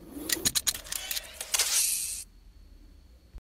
Механическая рука-манипулятор